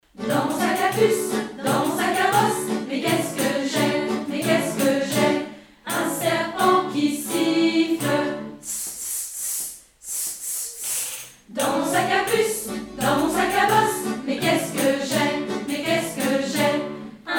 Ce chant à accumulation